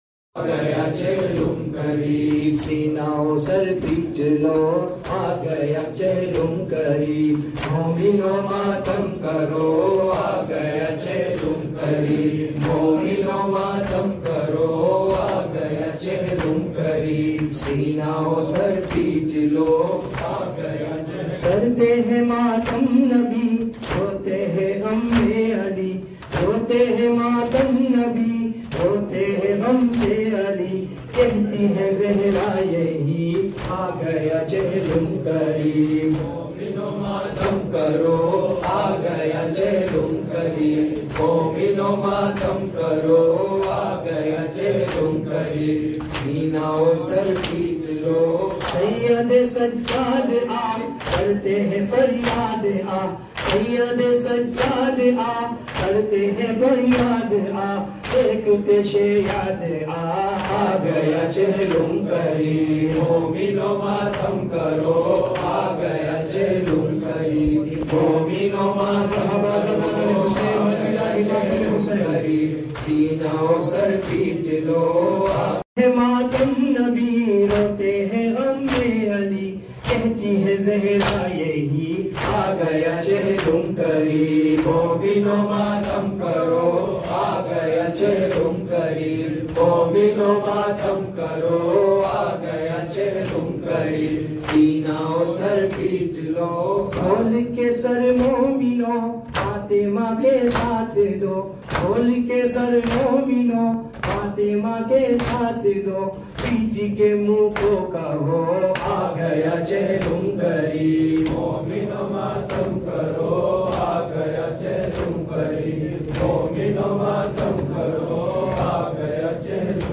Chehlum / Arbaeen